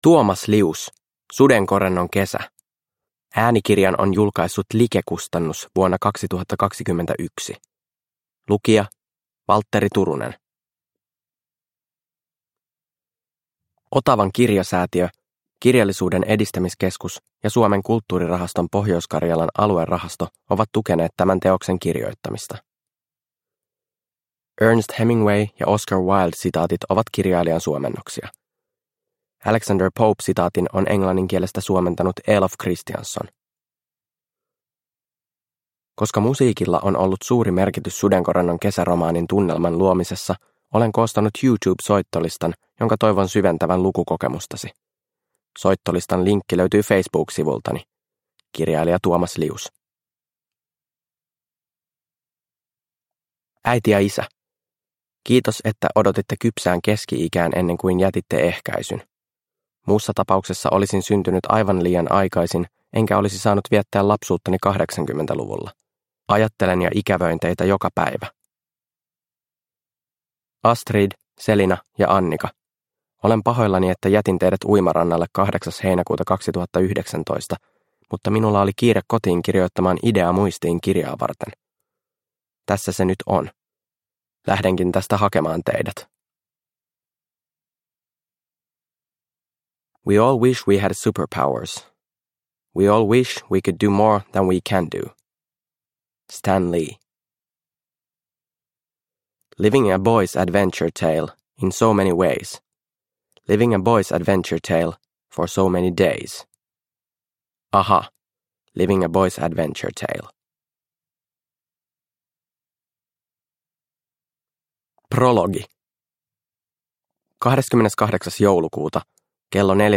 Sudenkorennon kesä – Ljudbok – Laddas ner